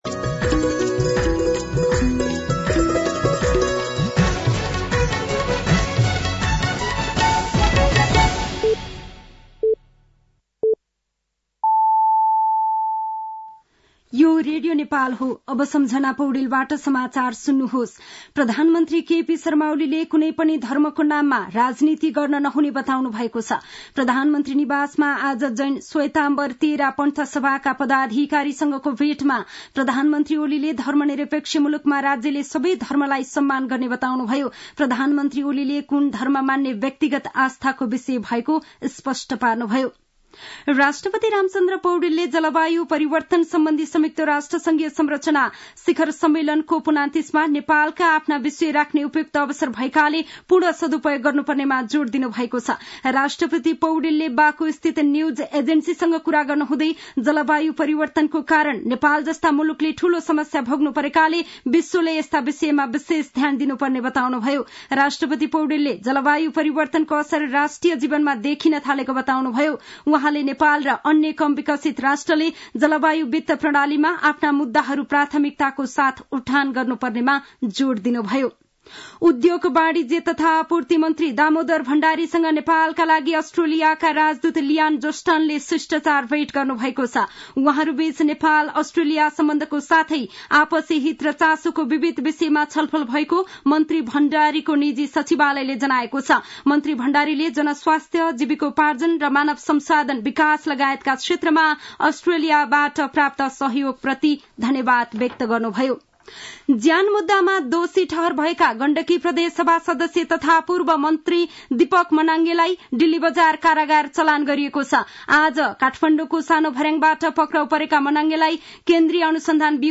साँझ ५ बजेको नेपाली समाचार : २८ कार्तिक , २०८१